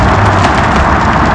1 channel
snowslide.mp3